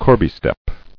[cor·bie-step]